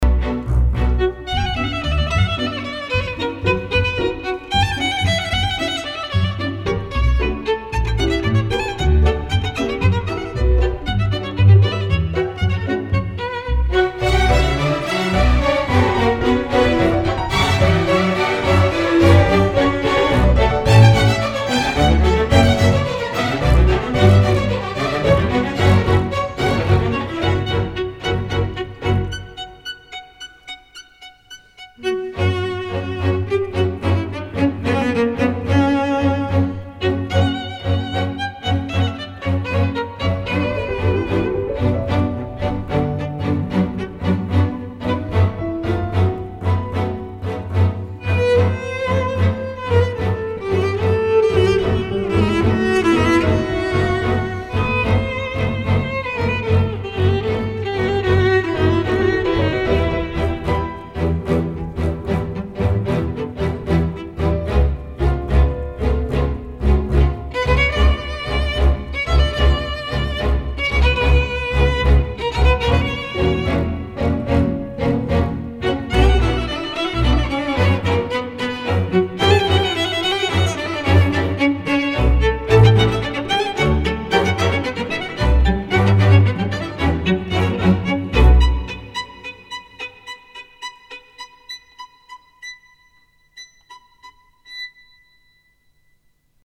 Пишем оркестр, но сам оркестр Казанский!
Так же прекрепил ещё не сведенную запись, фрагмент. ПО сути исхожник, есть только дополгнительный ревер и нремного низ на мастере приподнял!